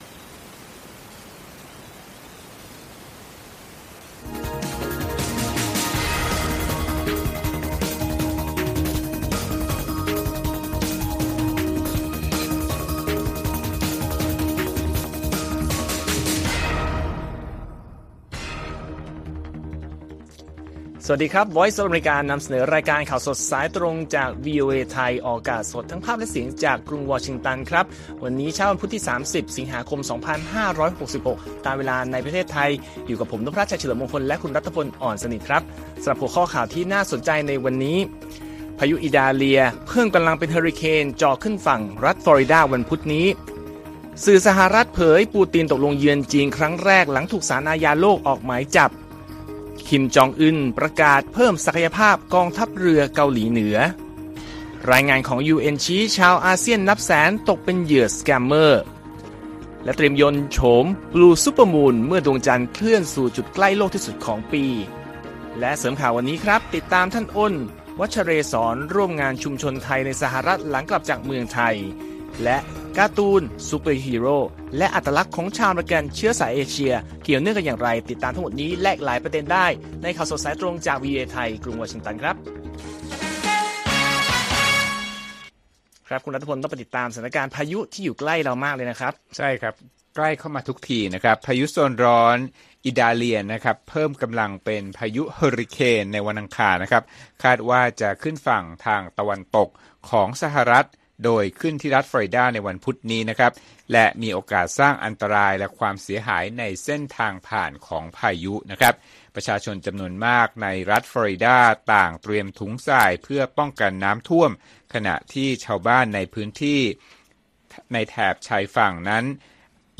ข่าวสดสายตรงจากวีโอเอไทย 6:30 – 7:00 น. วันที่ 30 ส.ค. 2566